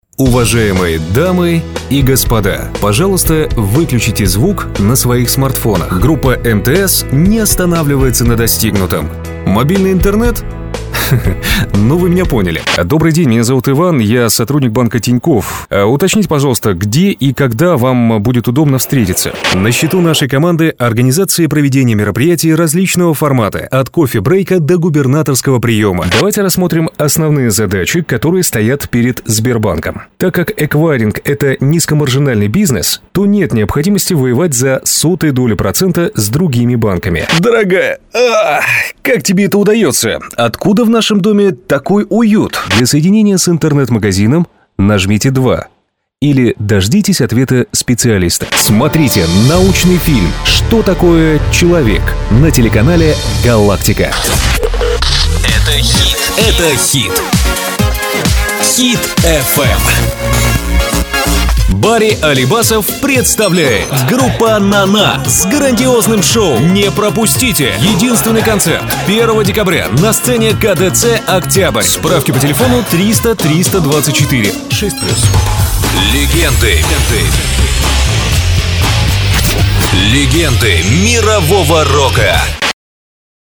Пример звучания голоса
Муж, Другая/Молодой
Отличный профессиональный звук: микрофон - Neumann TLM 103, микрофонный процессор - PreSonus ADL 700, звуковая карта - Steinberg UR12, тон-кабина.